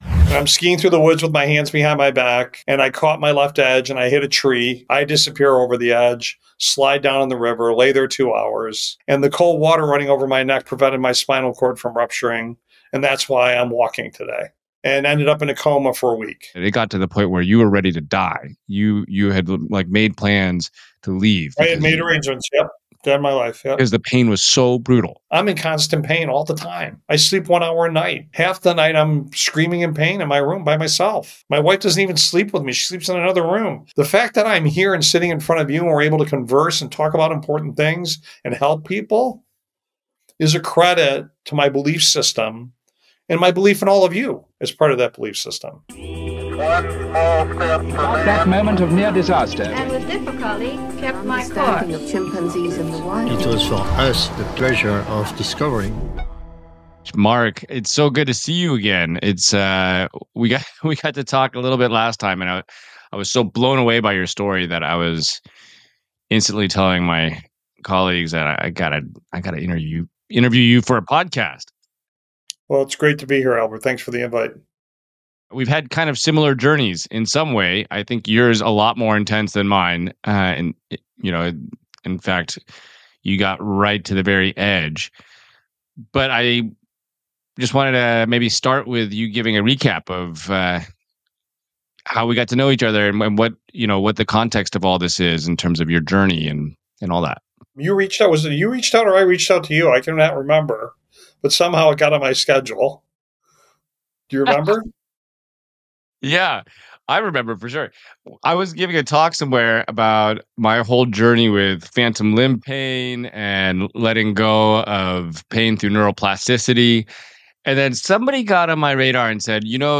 In this episode of the Explorer Albert podcast, host Albert Lin sits down with Mark T. Bertolini, the CEO of Oscar Health and a former leader of Aetna, to explore an extraordinary journey through unimaginable pain and resilience.